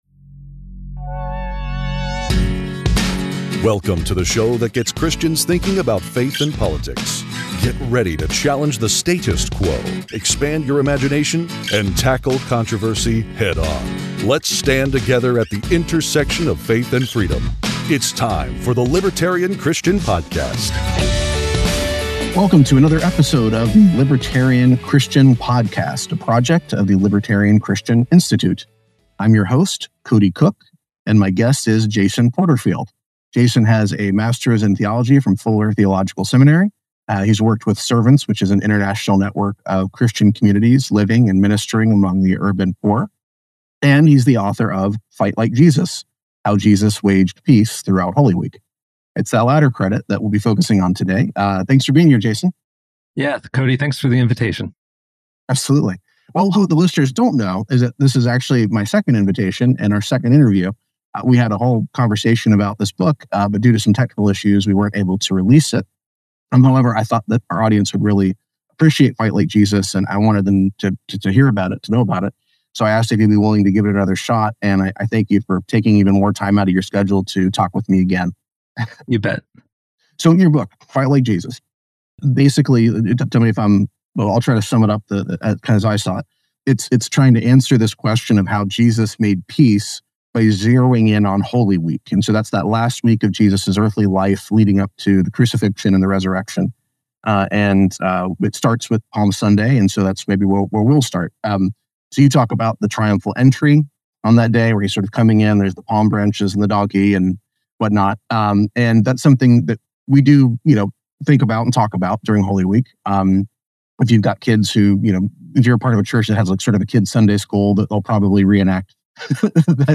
Don’t miss this enlightening discussion that promises to challenge and enrich your understanding of Christian peacemaking.